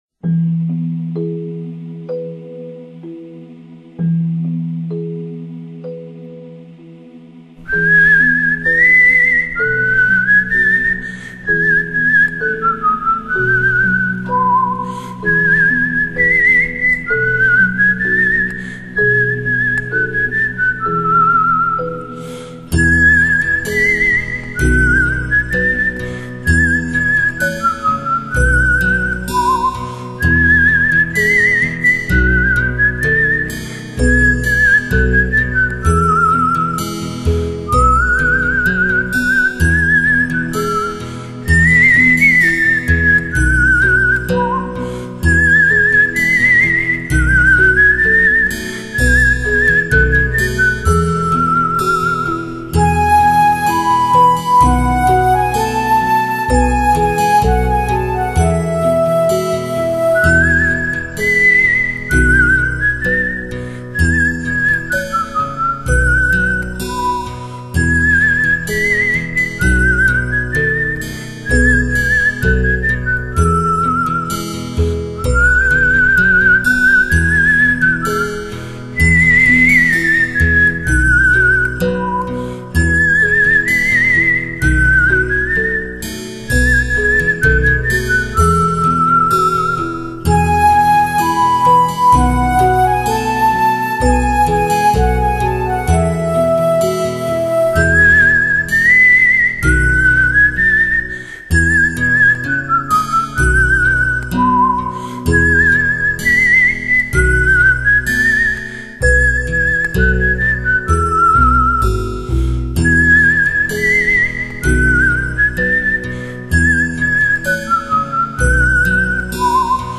极美动人，美妙如天籁般的空灵乐声